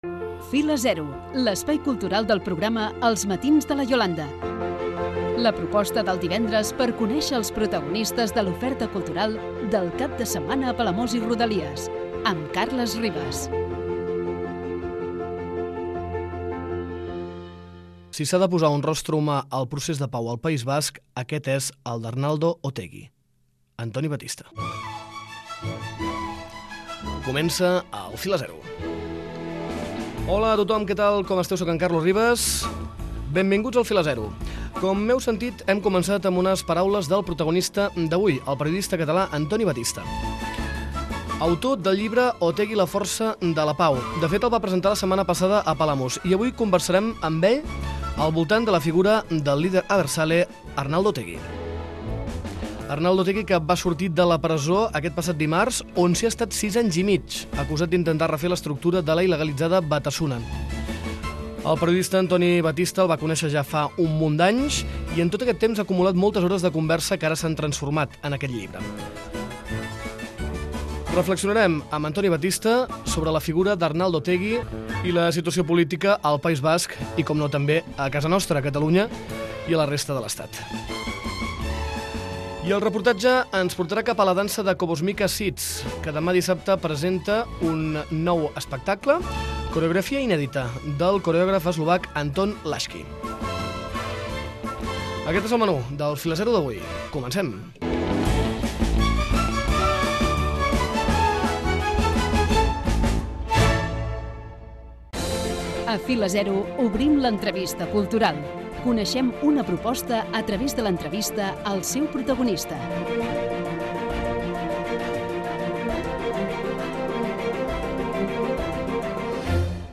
El reportatge setmanal el dediquem a "Cobosmika Seeds".